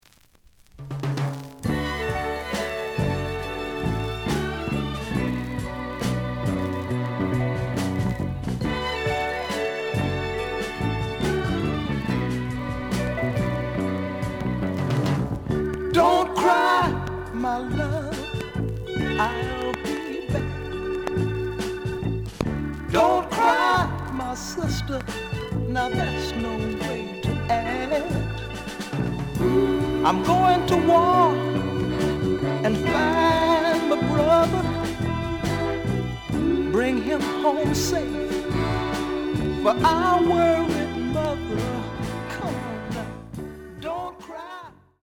The audio sample is recorded from the actual item.
●Genre: Soul, 60's Soul
Some click noise on beginning of both sides due to a pop.)